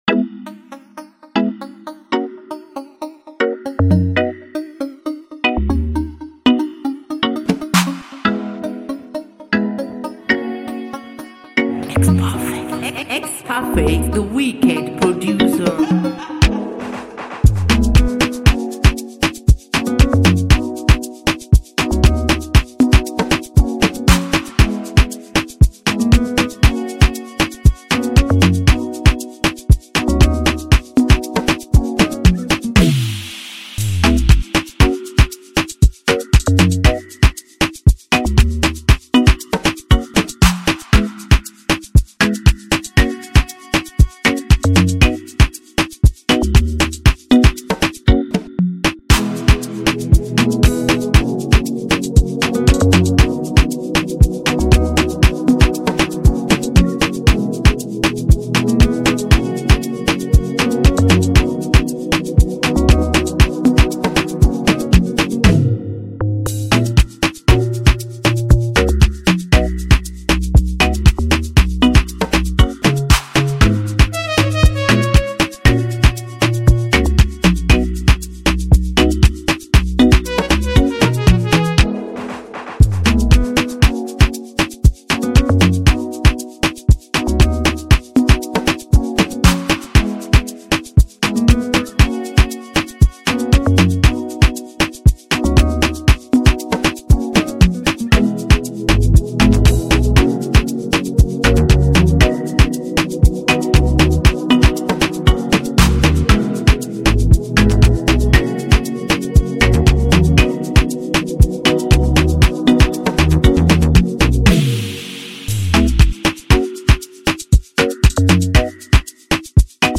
2021-11-28 1 Instrumentals 0
instrumental